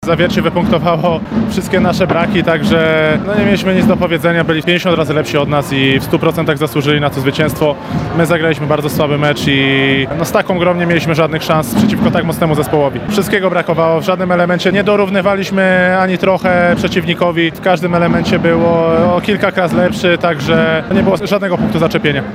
– Rywal był zdecydowanie lepszy – przyznaje kapitan lubelskiej drużyny Marcin Komenda.